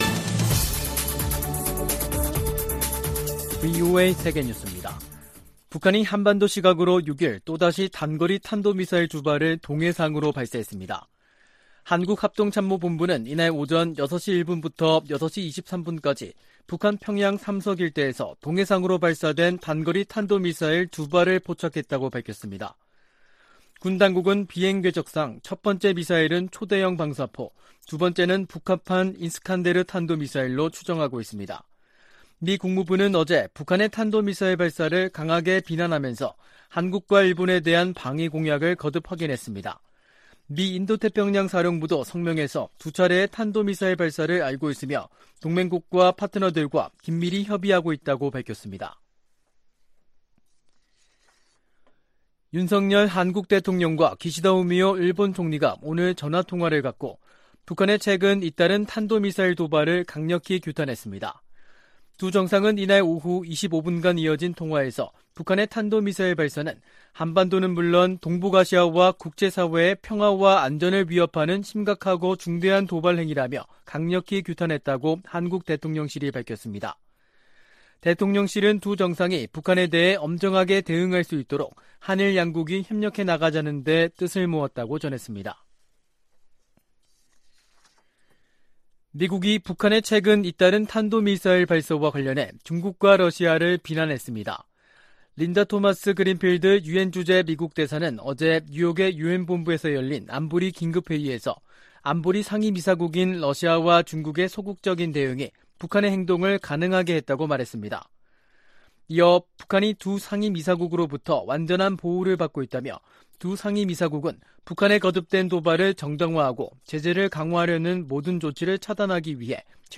VOA 한국어 간판 뉴스 프로그램 '뉴스 투데이', 2022년 10월 6일 3부 방송입니다. 북한이 또 다시 단거리 탄도미사일 두발을 동해상으로 발사했습니다. 유엔 안보리가 북한의 탄도미사일 발사에 대응한 공개회의를 개최하고 북한을 강하게 규탄했습니다.